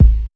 just blaze deepkick808ish.WAV